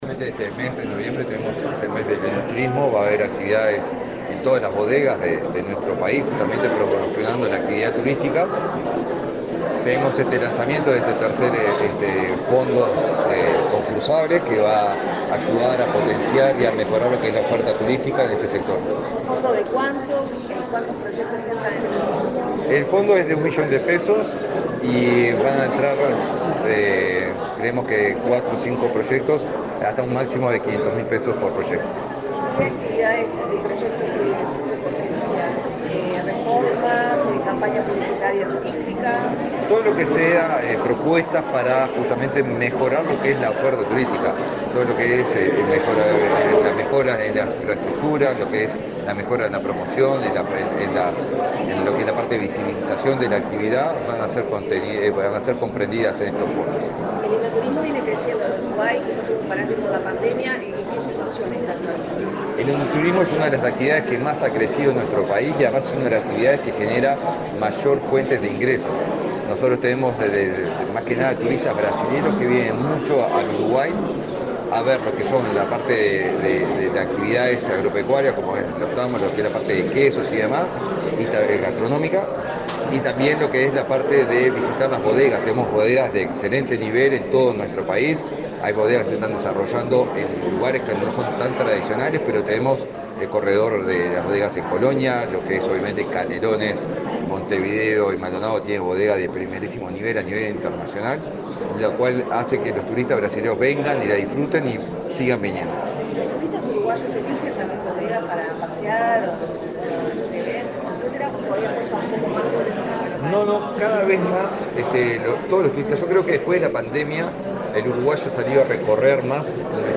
Declaraciones del ministro de Turismo, Eduardo Sanguinetti
El ministro de Turismo, Eduardo Sanguinetti, dialogó con la prensa, antes de participar del lanzamiento del calendario de actividades del evento